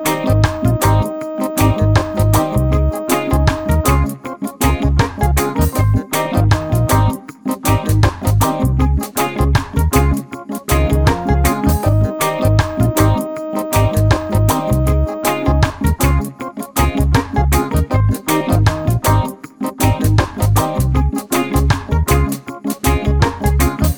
Minus Solo Guitar Reggae 4:07 Buy £1.50